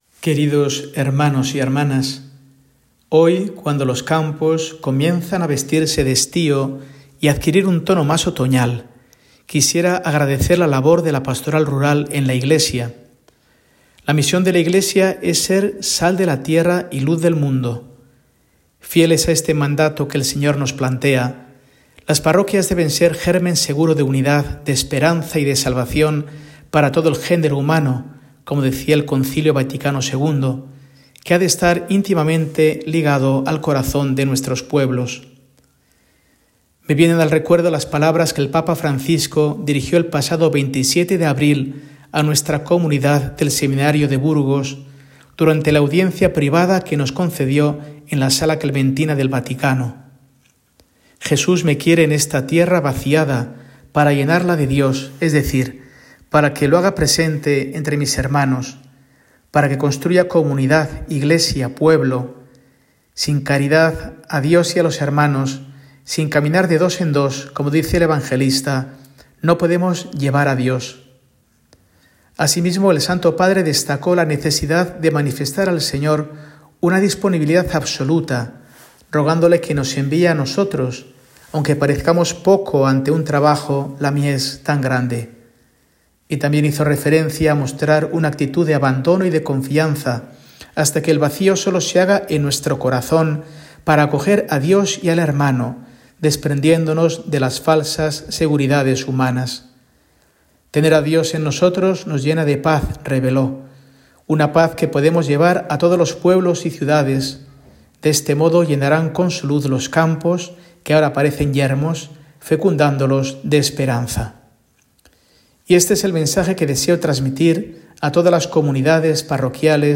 Mensaje semanal de Mons. Mario Iceta Gavicagogeascoa, arzobispo de Burgos, para el domingo, 22 de septiembre de 2024